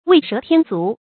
為蛇添足 注音： ㄨㄟˊ ㄕㄜˊ ㄊㄧㄢ ㄗㄨˊ 讀音讀法： 意思解釋： 見「為蛇畫足」。